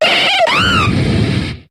Cri de Simiabraz dans Pokémon HOME.